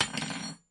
餐具的声音 " 大勺子1
Tag: 餐具